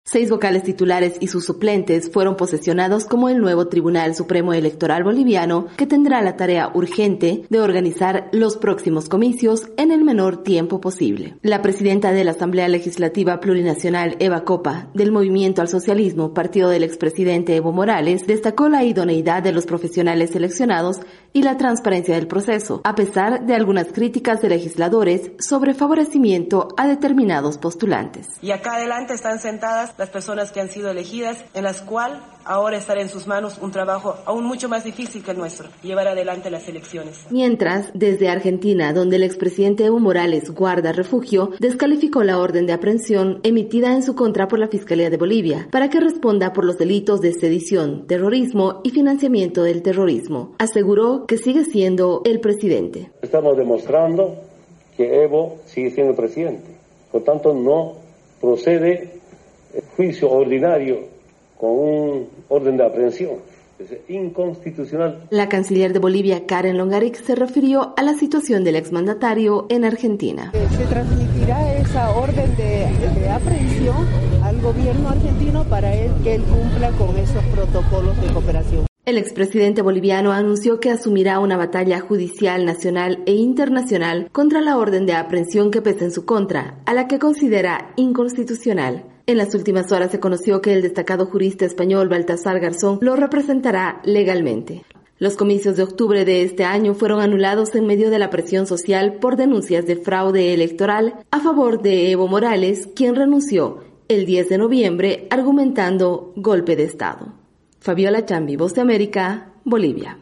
VOA: Informe de Bolivia